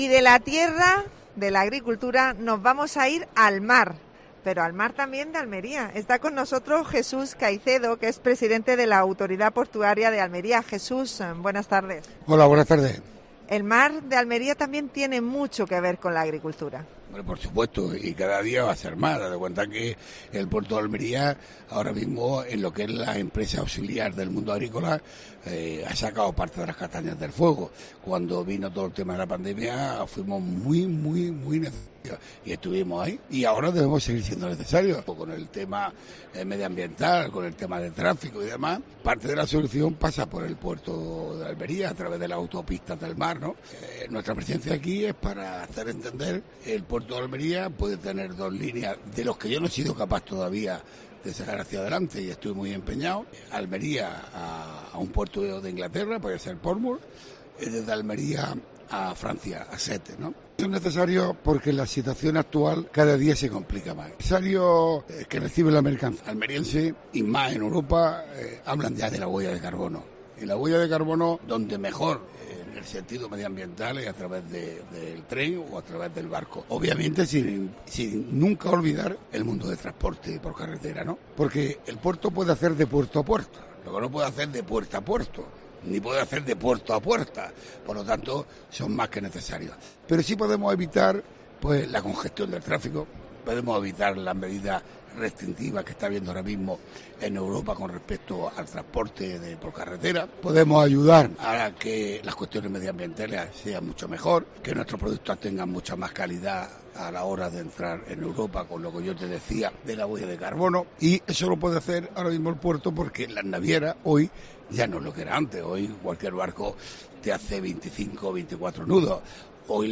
Entrevista a Jesús Caicedo (presidente de la Autoridad Portuaria de Almería).